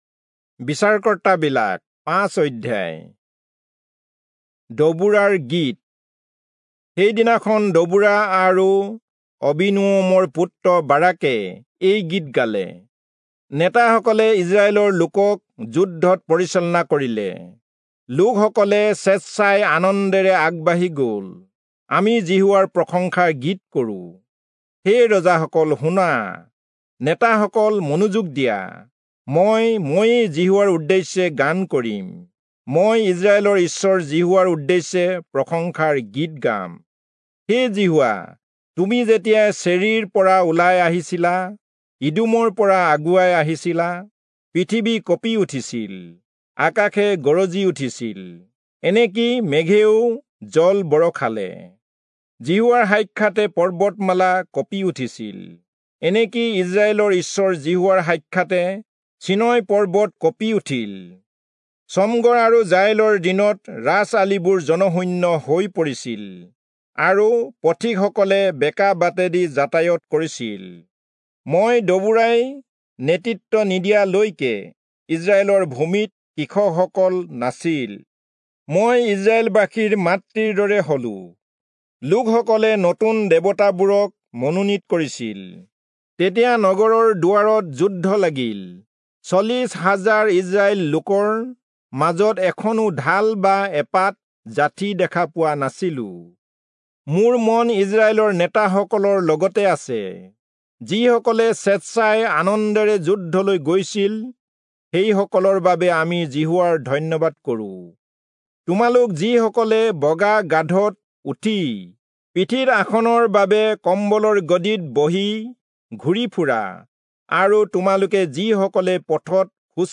Assamese Audio Bible - Judges 21 in Alep bible version